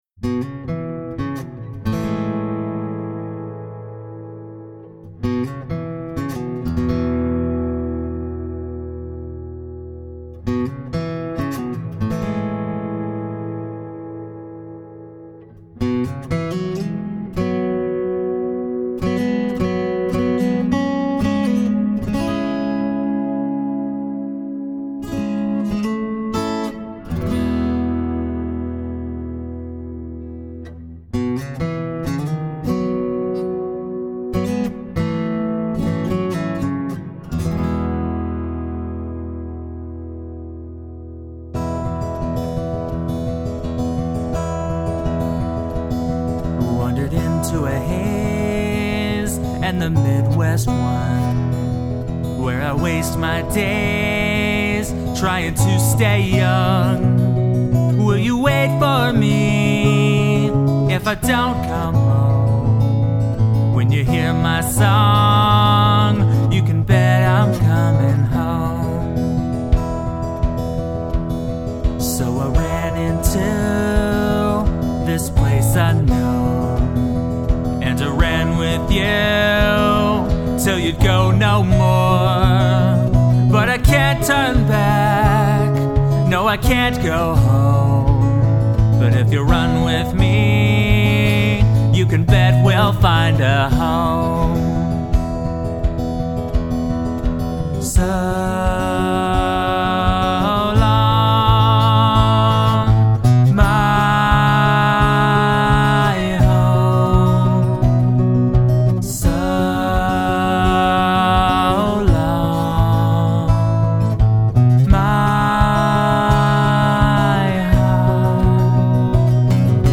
epic rock “anthems.”
recorded in my small rental house in Austin, Texas
Acoustic Demo